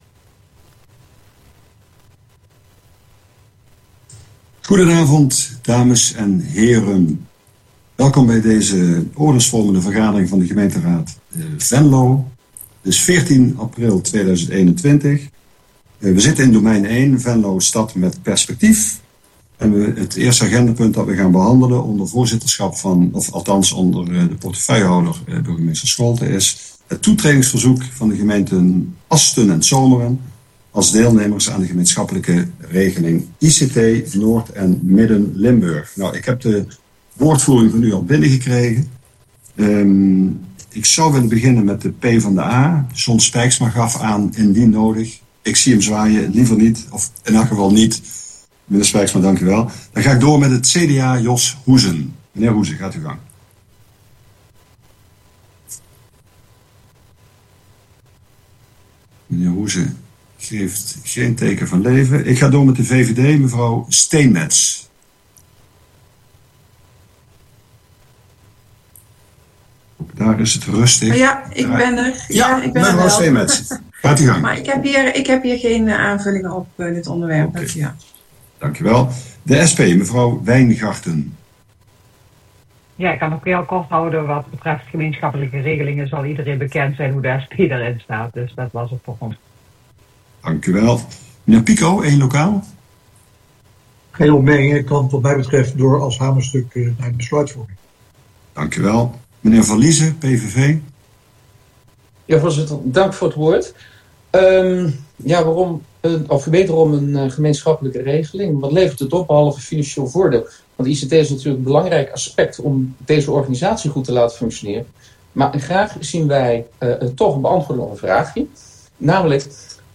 Oordeelsvormende raadsvergadering 14 april 2021 19:00:00, Gemeente Venlo
Portefeuillehouder: burgemeester Antoin Scholten Sessievoorzitter: Harro Schroeder